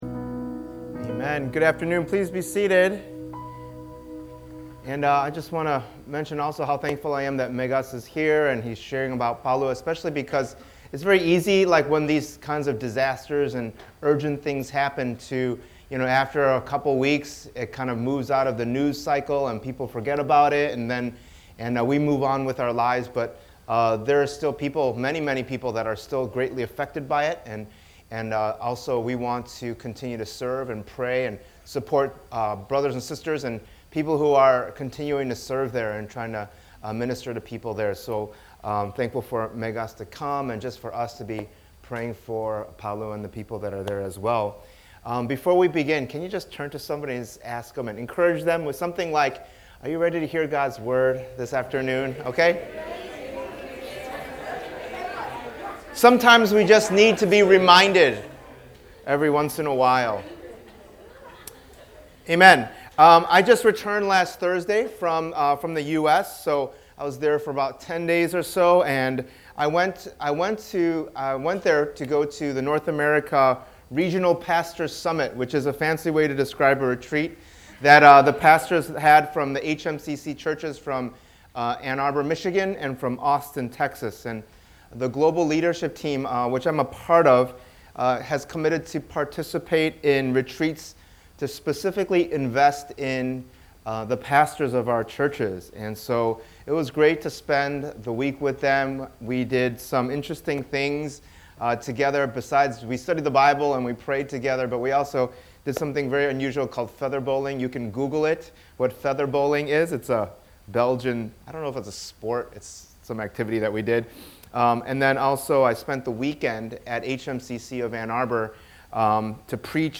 In this sermon series, we’ll be looking through Apostle Peter’s First Letter to different churches in the first century amidst a time of great suffering and persecution, and we’ll see different aspects of what God calls us to as disciples of Jesus Christ: 1: Experiencing Salvation 2: Growing Up Into Salvation 3: Knowing Who We Are Together 4: Living as Servants 5: Living as Wives & Husbands 6: Suffering for Doing Good 7: Glorifying God in Everything 8: Suffering as a Christian 9: Standing Firm as the Church